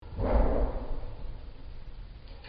Download Bed Banging sound effect for free.
Bed Banging